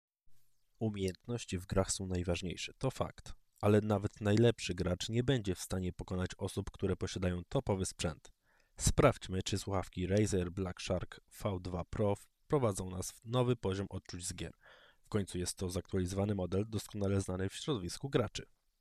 Muszę przyznać, jakość, jaką oferuje ten model to klasa sama w sobie. Nie ma praktycznie żadnych zakłóceń, eliminacja szumów pracuje wyśmienicie, wyciszenie dźwięków z zewnątrz działa wzorowo.